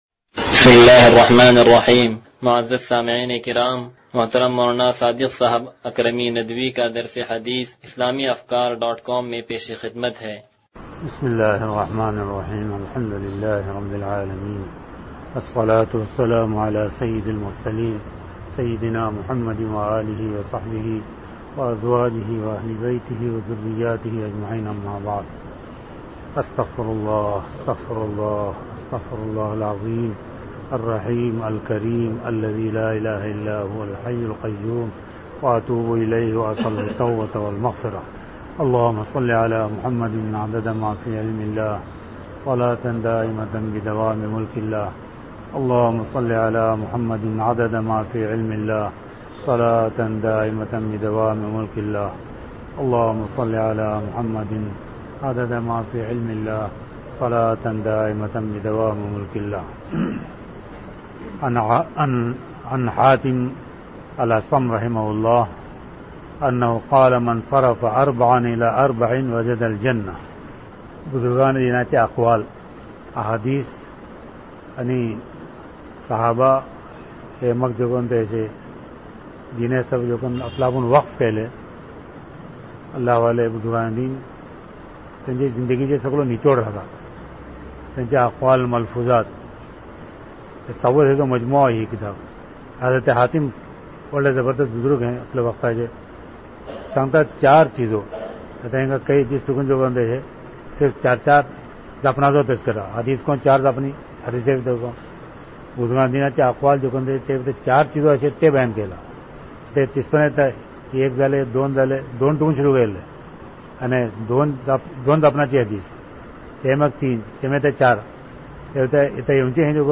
درس حدیث نمبر 0088